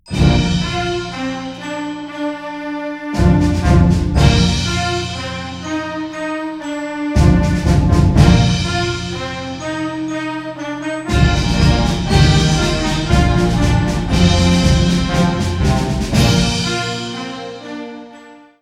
Categorie Harmonie/Fanfare/Brass-orkest
Subcategorie Concertmuziek
Bezetting Ha (harmonieorkest); YB (jeugdorkest)